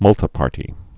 (mŭltə-pärtē)